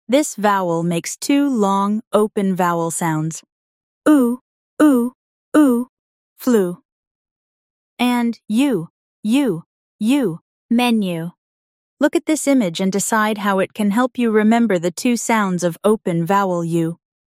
This vowel make two long open vowel sounds:
U-flu-menu-lesson.mp3